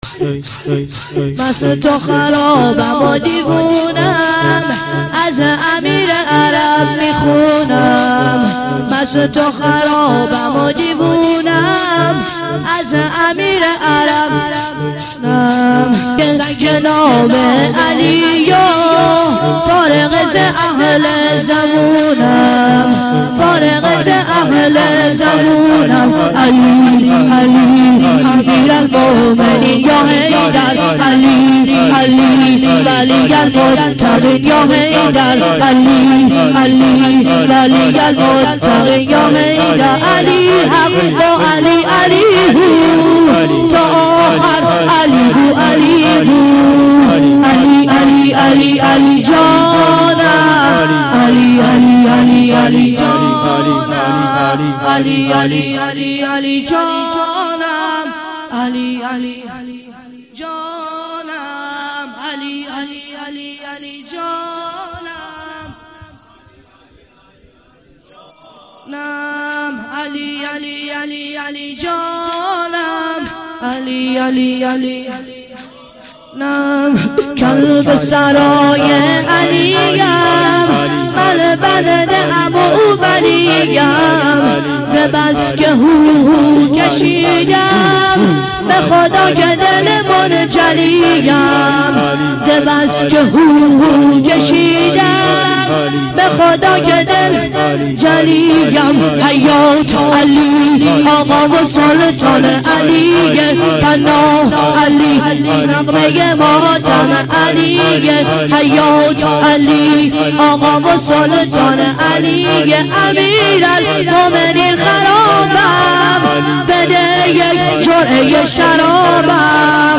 شور - مست و خرابم و دیونه